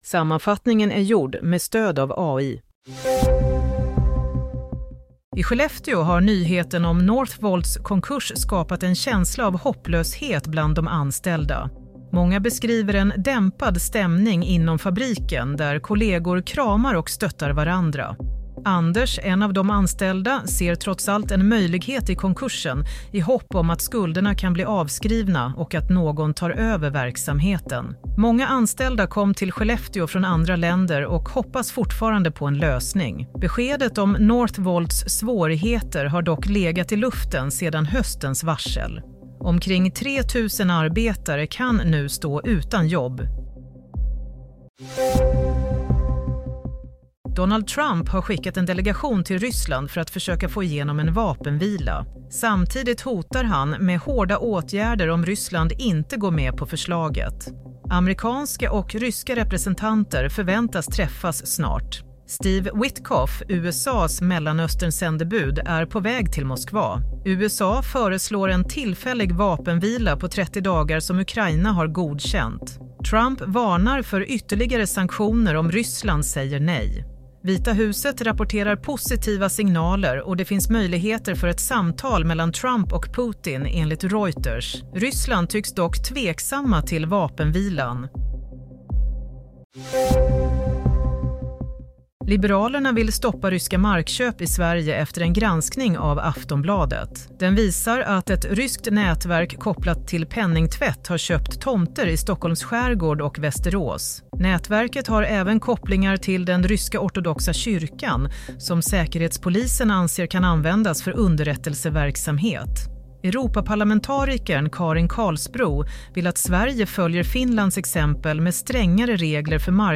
Nyhetssammanfattning - 12 mars 22:00